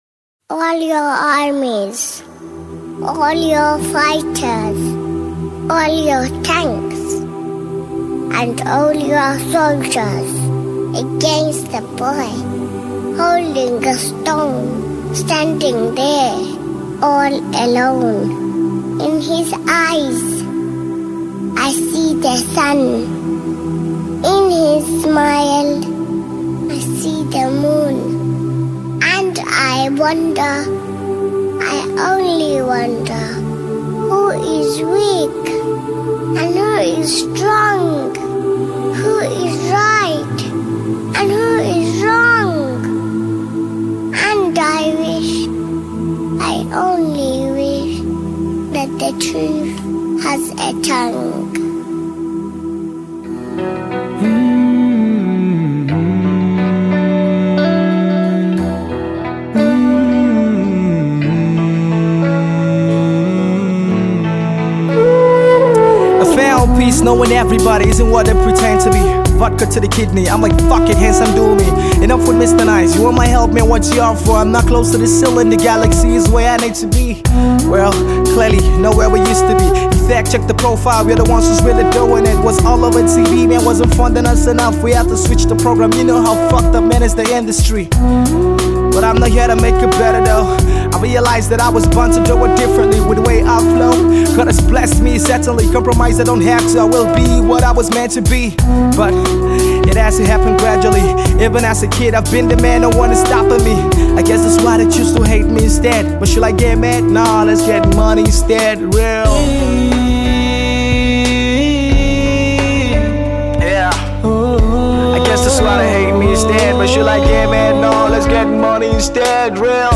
Hip-Hop, Rap